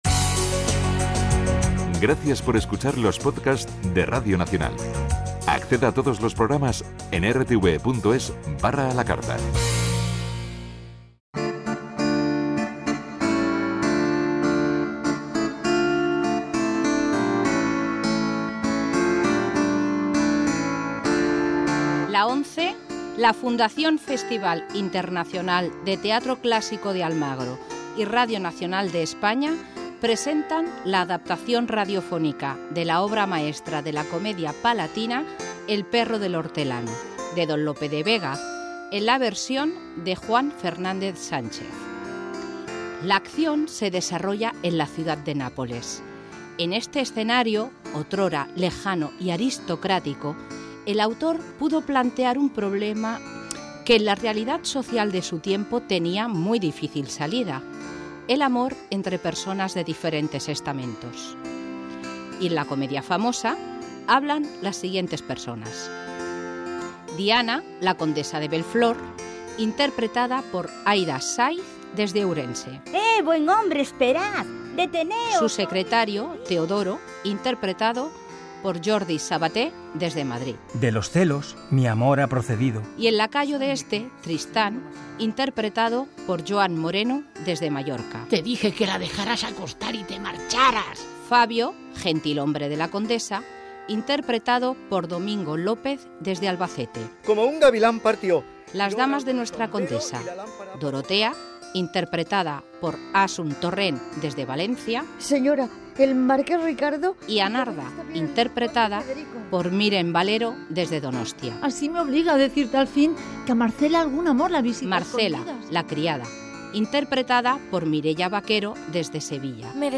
Dramatización "El perro del hortelano"